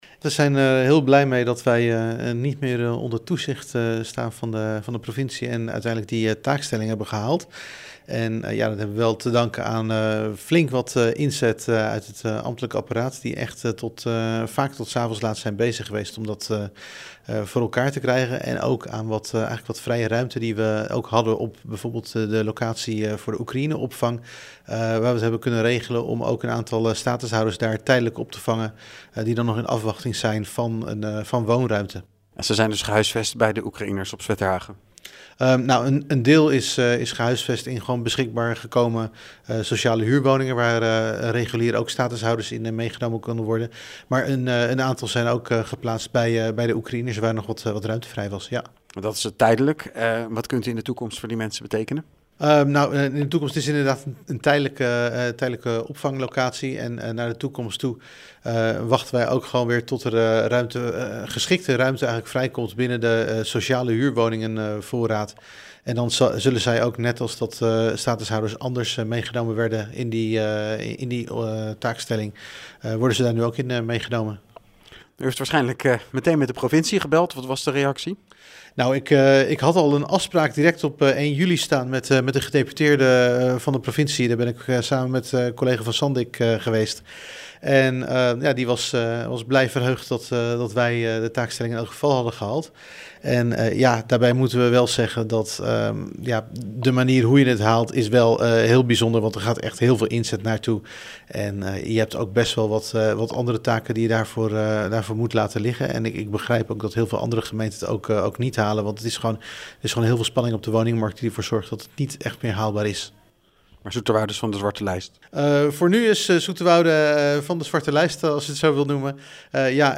AUDIO: Wethouder Paul Olthof over het vervullen van de taakstelling huisvesting statushouders.